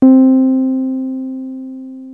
303 C4  6.wav